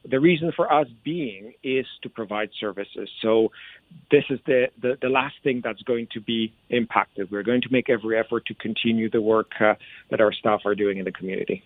myFM spoke with health unit CEO and Medical Officer of Health Dr. Pitor Oglaza, who says they do have funding from the province that will give them time to sort things out.